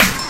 Track 14 - Hit FX OS 01.wav